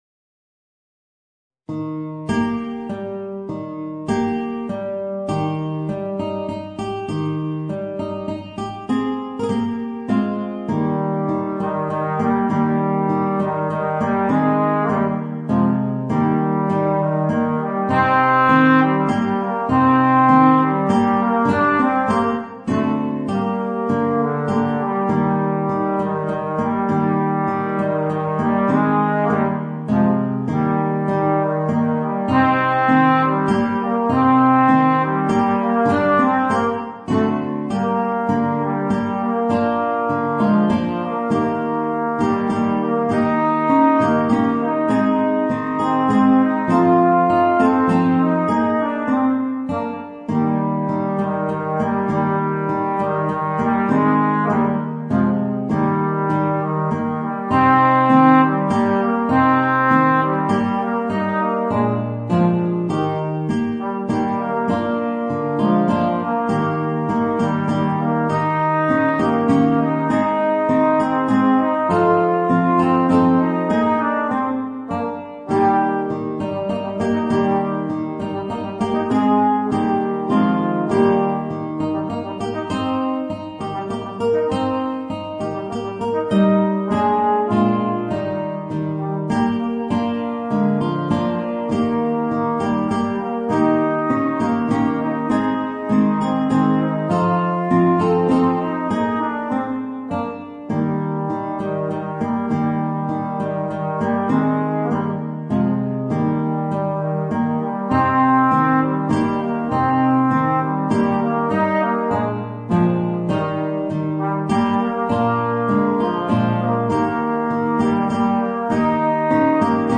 Voicing: Trombone and Guitar